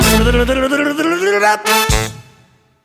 Mini sonnerie